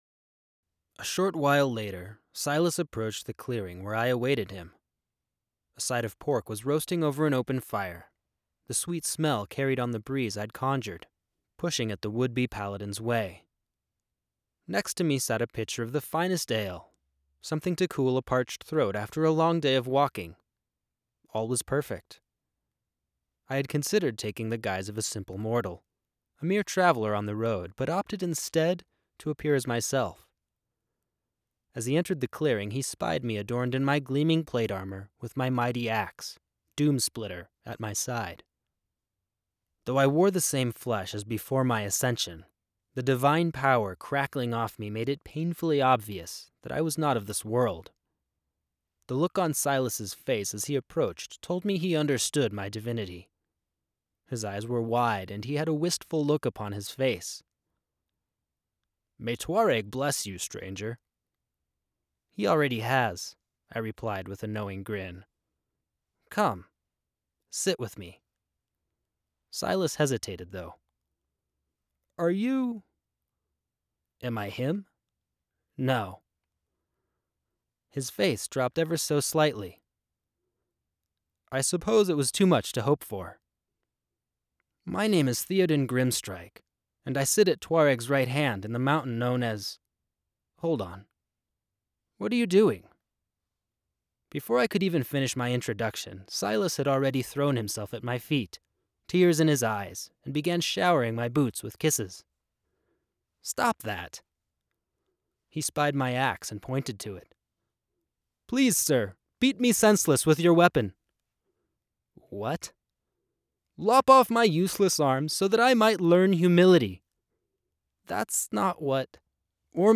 Here is an audio sample recorded in the booth, with no processing. Only normalization is applied: